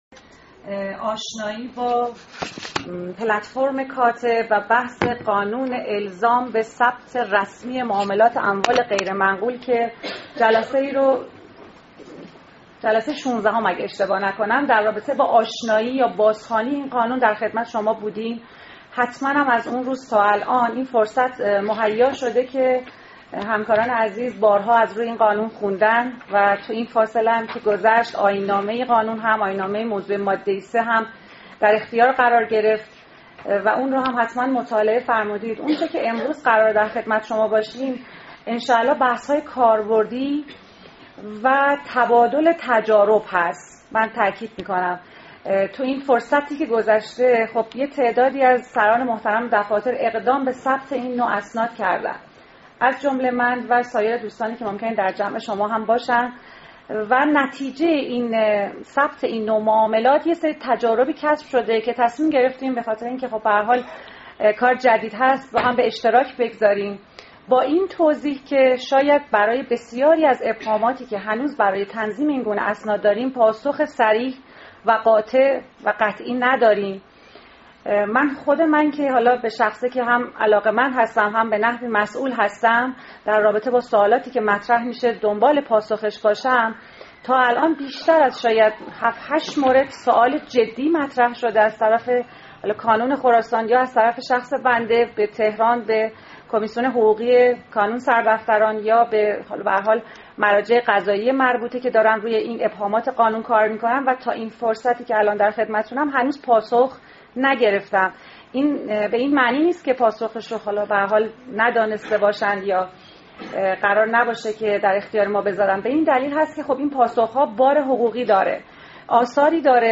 فایل صوتی کارگاه آموزشی شماره ۱۸